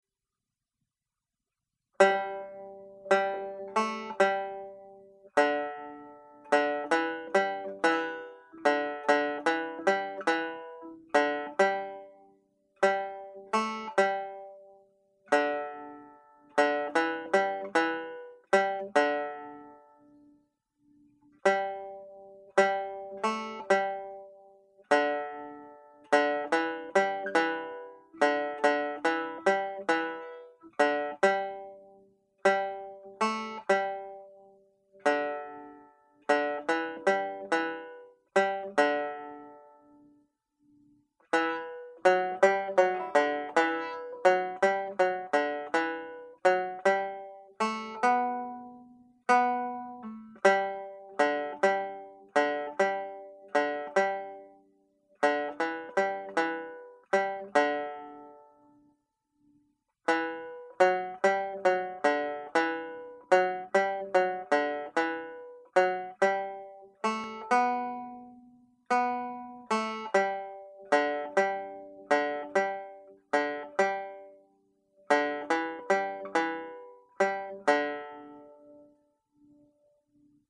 (harmony)
Harmony part played slowly